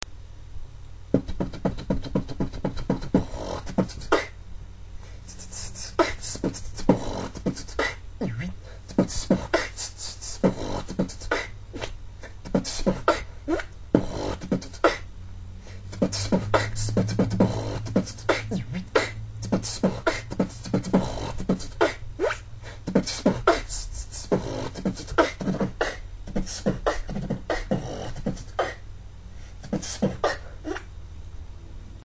Форум российского битбокс портала » Реорганизация форума - РЕСТАВРАЦИЯ » Выкладываем видео / аудио с битбоксом » Ну и мой биток зацените что ли ))
bt bt bt bt bt bt bt bt
brr t btt kch ts ts ts ts kch ts ts ts kch
brr t btt kch ts ts ts ts kch tsb ts ts kch bts kch
очевидный косяк : плохой басс у brr и неудачный первый вуть
кик + хайхэт
Сильно различается бит в теме и в аудио =)